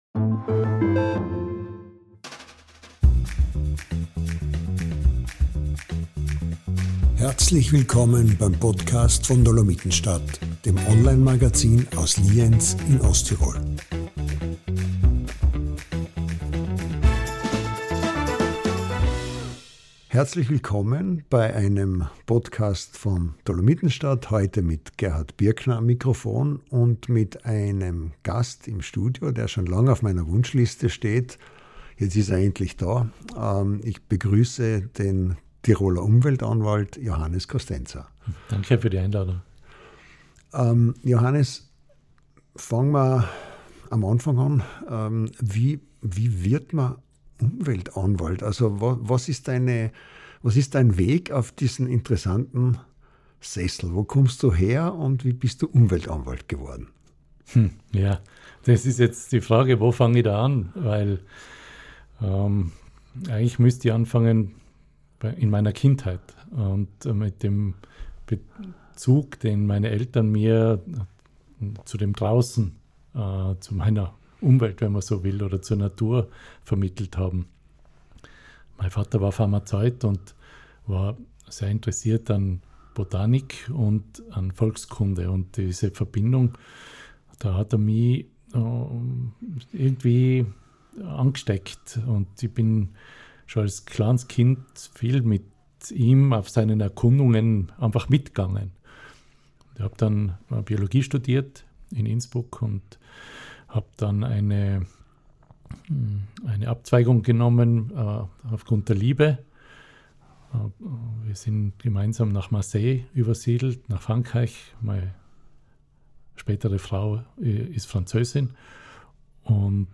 Beschreibung vor 1 Jahr In diesem Podcast kommt ein Mann zu Wort, dessen Rolle und Amt zum einen nicht allen bekannt und zum anderen bei nicht bei allen beliebt ist: Johannes Kostenzer ist Tiroler Umweltanwalt.